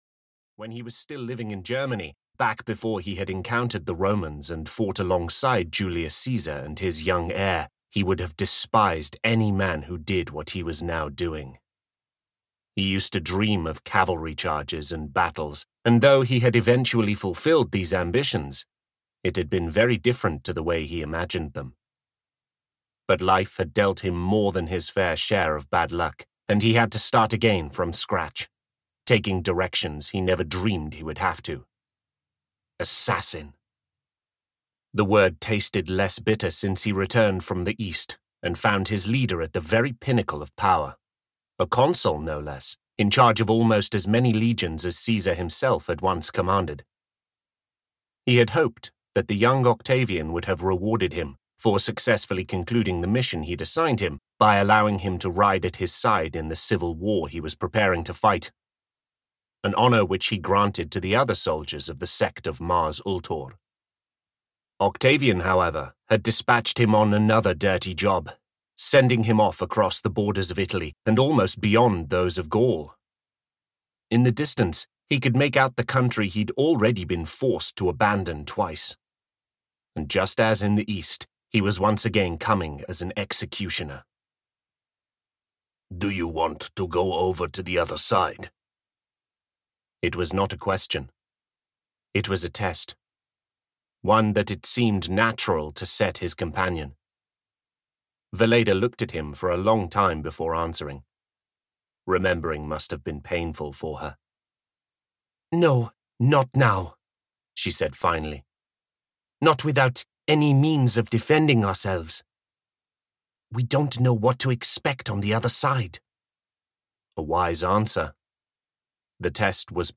Revenge (EN) audiokniha
Ukázka z knihy